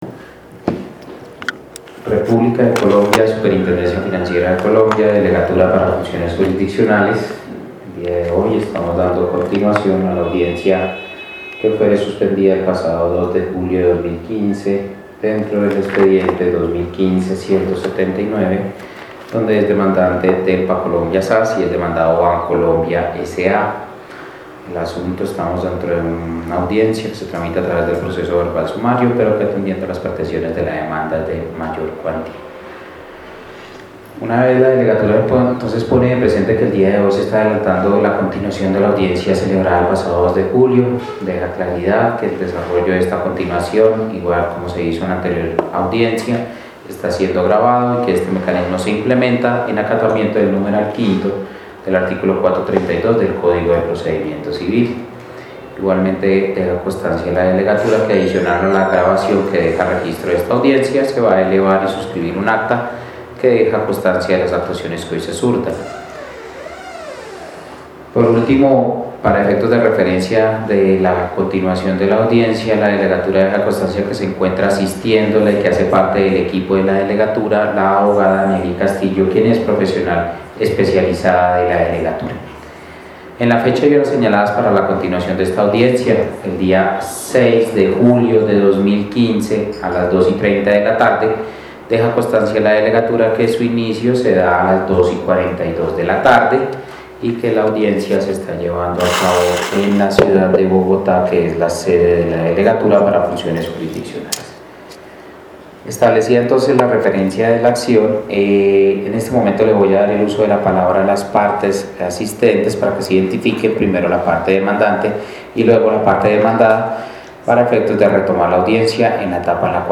Por lo tanto, indicó la superintendencia, la falsedad debe ser realmente evidente y palmaria, de tal forma que pueda ser detectada a simple vista, sin que se requiera una calificación adicional. Nota: Puede escuchar la lectura de la decisión en el siguiente enlace o descargar el audio: Descarga el documento ¿Quieres descargar este documento?